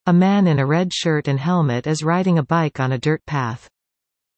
Finally, I converted the captions to speech by passing them through Google’s WaveNet (text-to-speech algorithm) available on the Google Cloud Platform, using Python’s text to speech library.